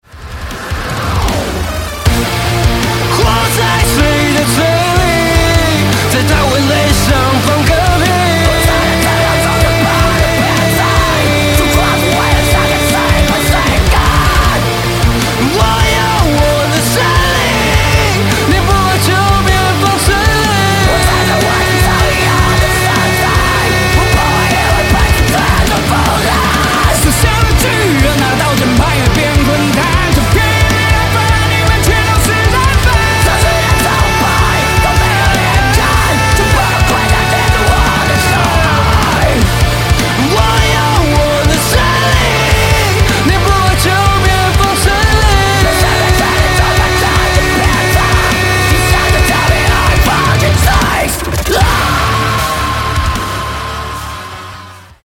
Alternative rock, Chinese pop